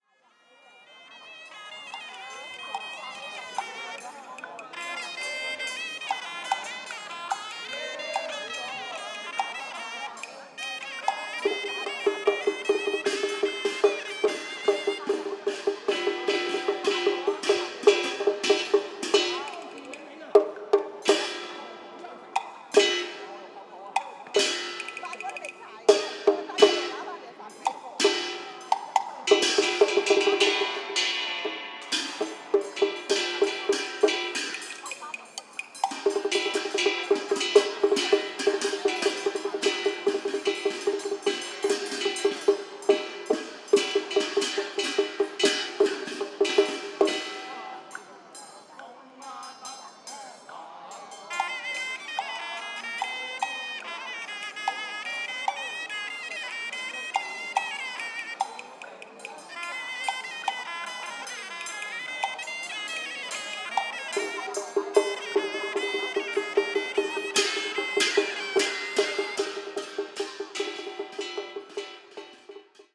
Chinese Traditional Ritual 1
chinese hong-kong ritual traditional sound effect free sound royalty free Memes